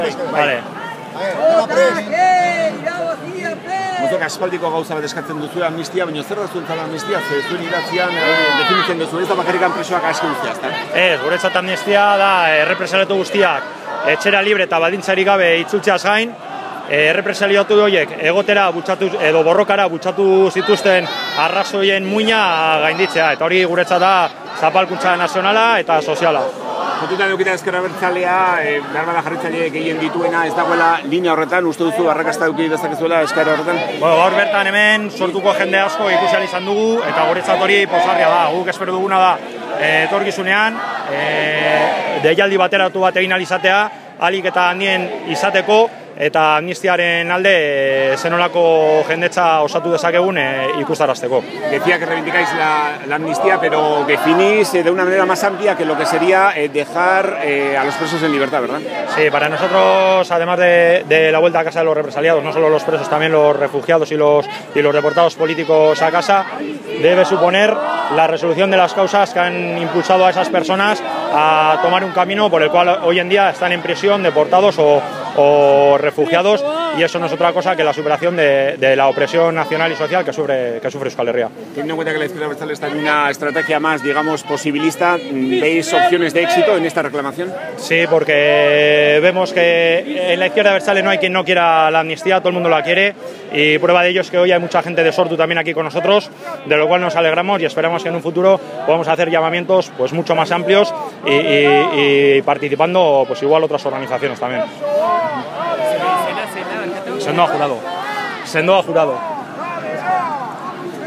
LH entrevista